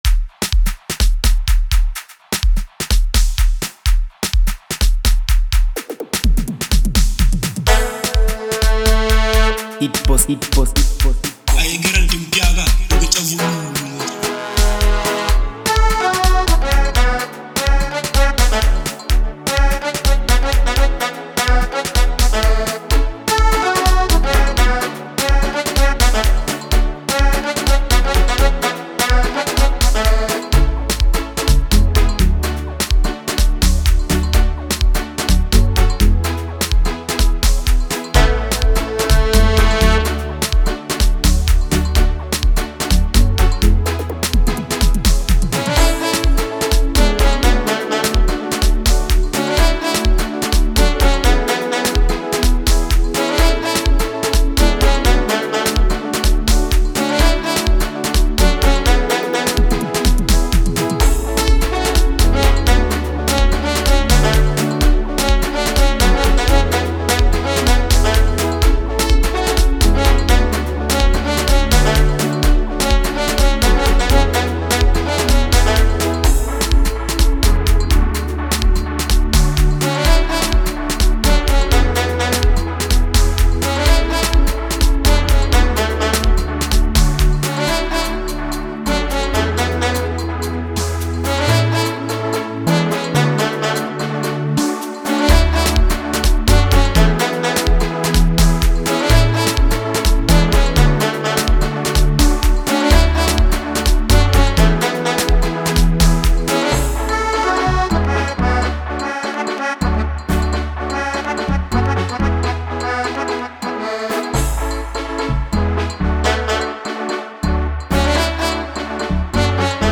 is a vibrant and confident track